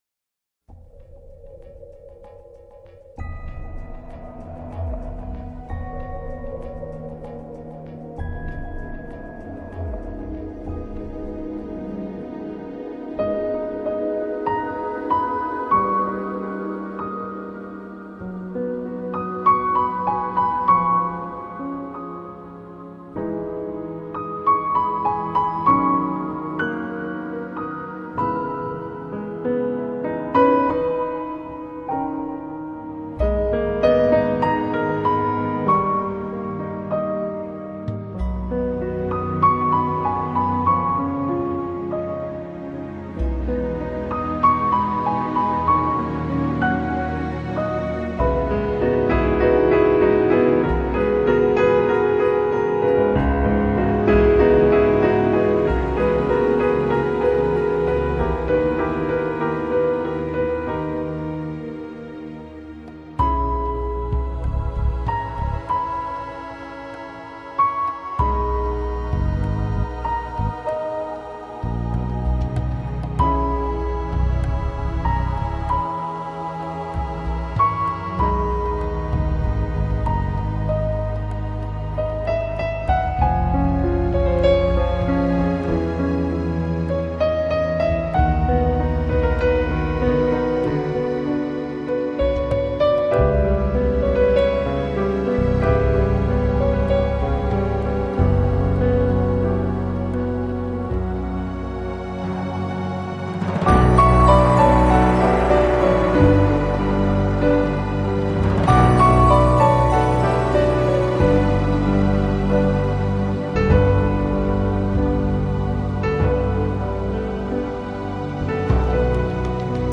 Файл в обменнике2 Myзыкa->Джаз
исполняющая музыку в стиле нью эйдж и джаз.